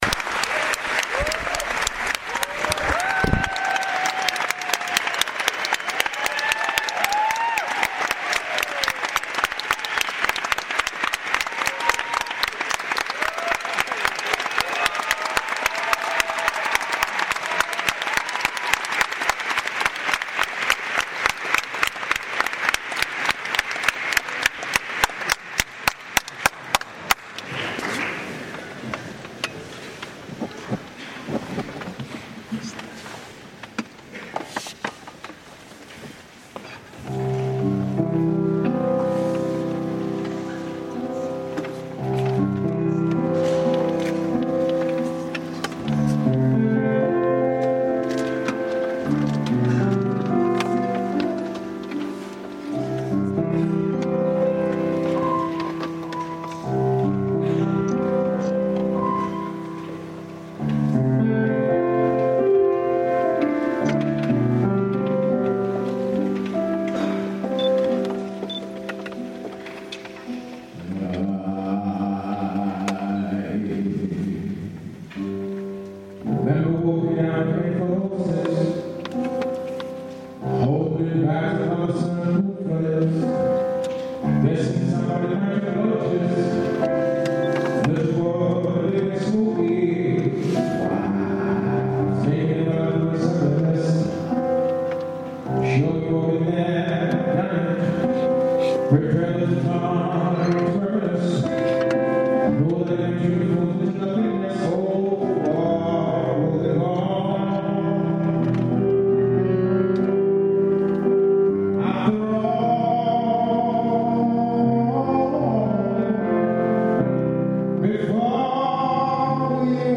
Registrazione audio del concerto
il suo corpo, il suo piano. e un batterista.